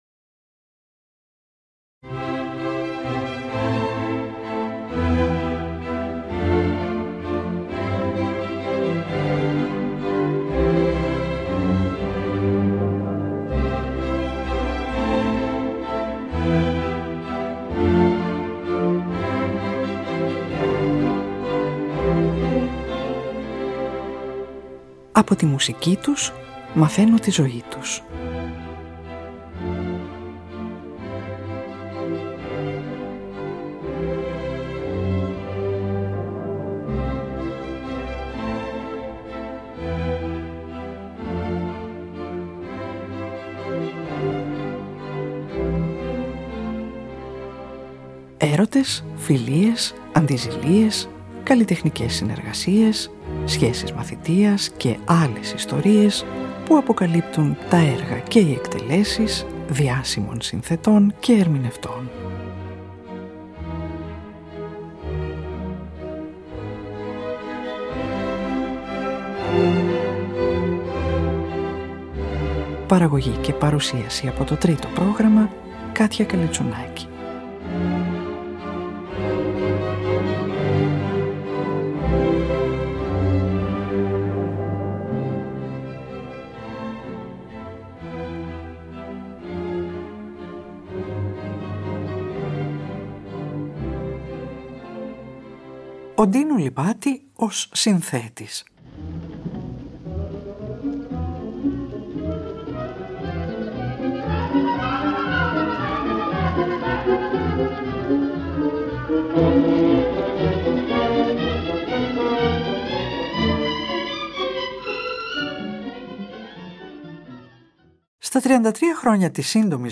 σε κλασικό στυλ
ηχογραφημένο ζωντανά
ηχογραφημένη ζωντανά